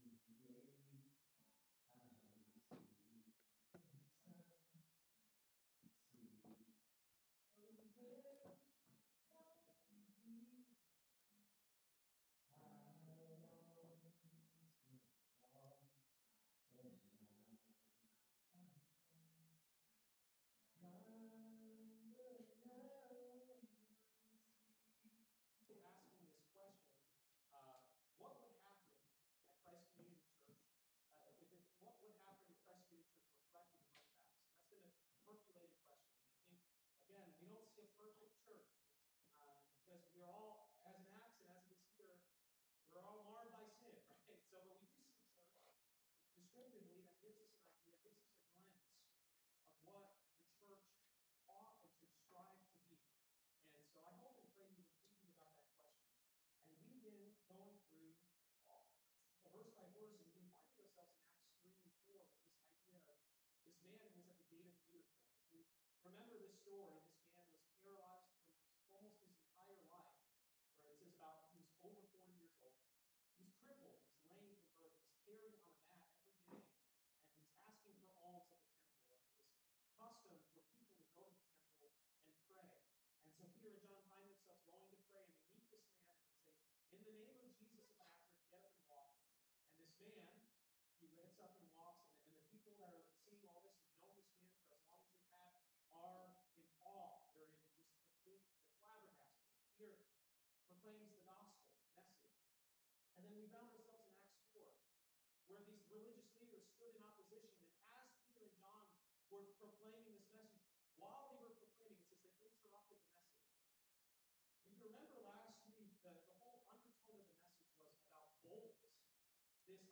Sunday Service Service Type: Sunday Morning « Boldness Perfect Unity » Submit a Comment Cancel reply Your email address will not be published.